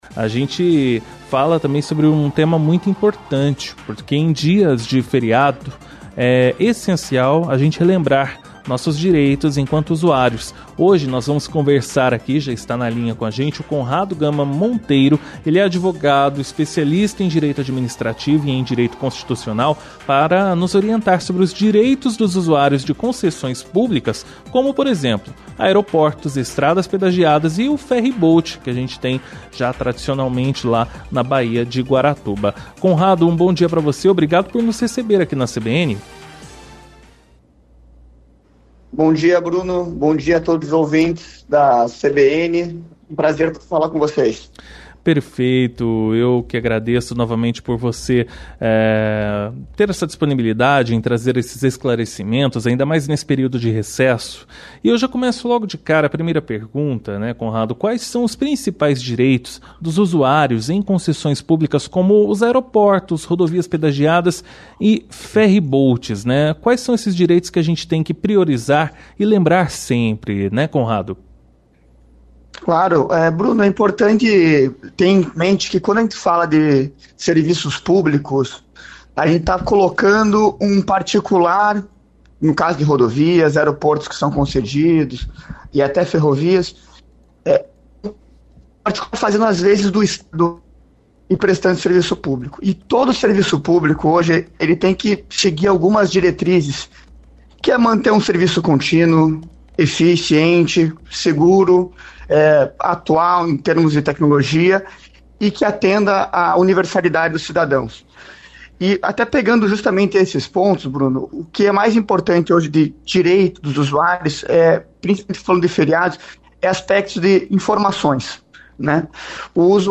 ENTREVISTA-DIREITOS-FERIADOS-0303.mp3